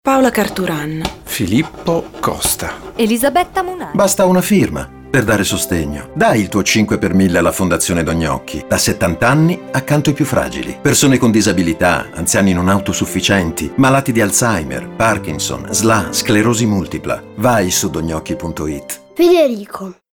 spot_radio_5_per_mille_2025.mp3